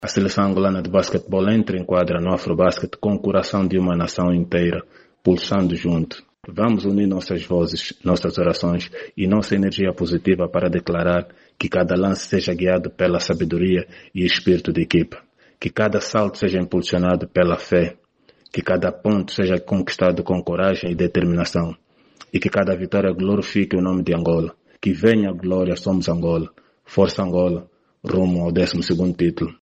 Entre elas está o antigo capitão Eduardo Mingas, que a partir de Saurimo, província da Lunda-Sul, manifestou a sua crença num resultado positivo e na capacidade da equipa em continuar a dignificar o nome de Angola.